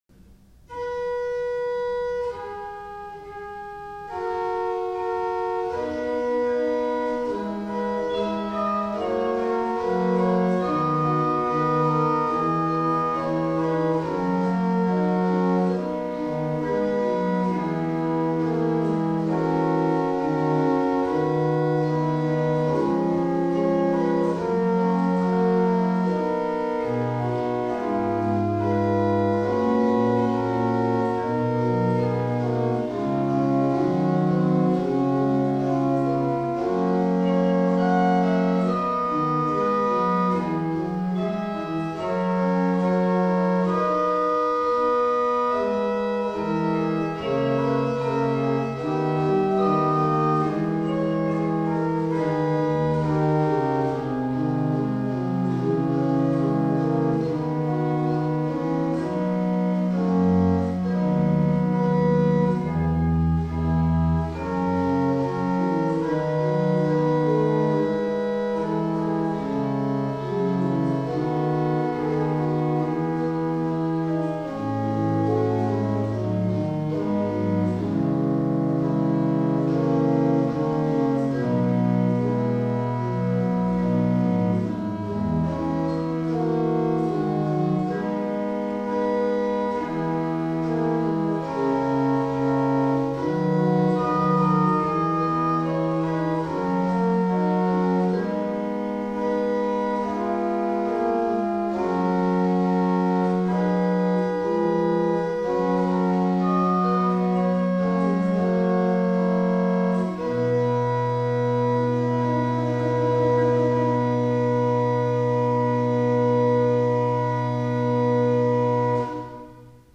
1800 Tannenberg Organ
Listen to the ricercar: Ave Maria klare by Johann Caspar Ferdinand Fischer played on the Principal 8' (front pipes) alone:
Ricercar: Ave Maria klare